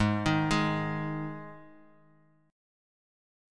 Ab5 chord